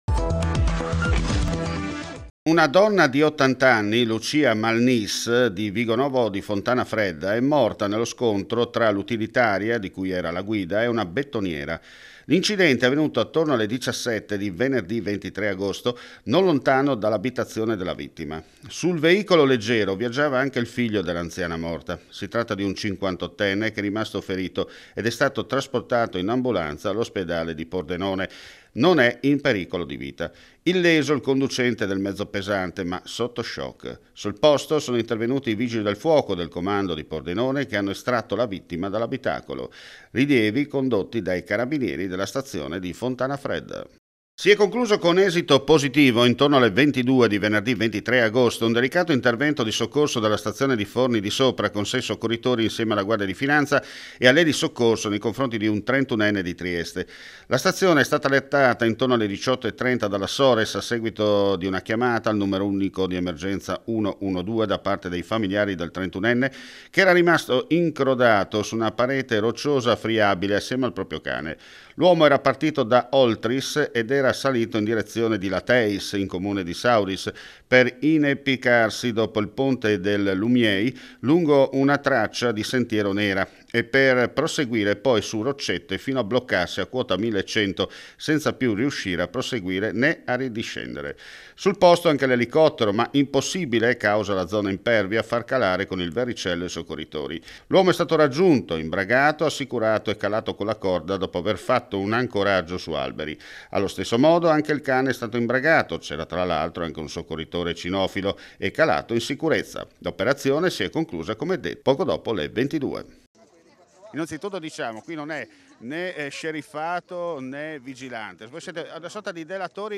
FRIULITV GIORNALE RADIO: LE ULTIME AUDIONOTIZIE DAL FRIULI VENEZIA GIULIA. IN APERTURA INCIDENTE MORTALE A FONTANFREDDA
INTERVISTA